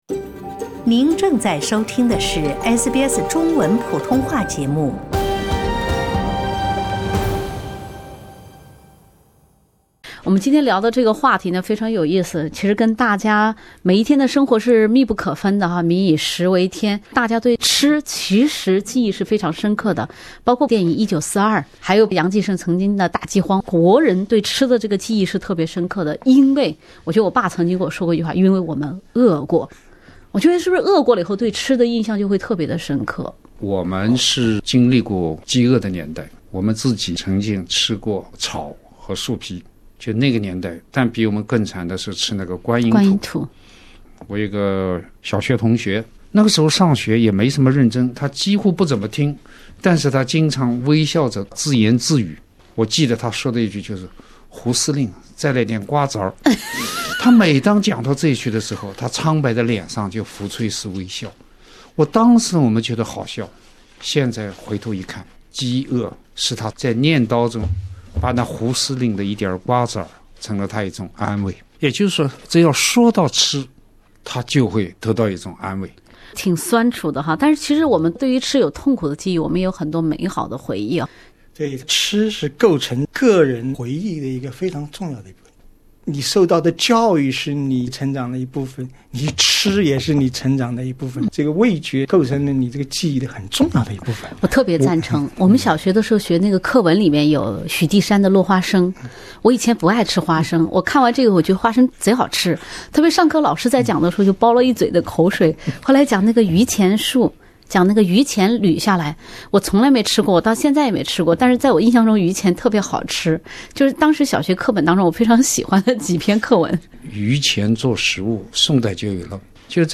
又到了一年中名正言顺“吃好喝好快躺下，每逢佳节胖三斤”的时节。（点击封面图片，收听完整对话）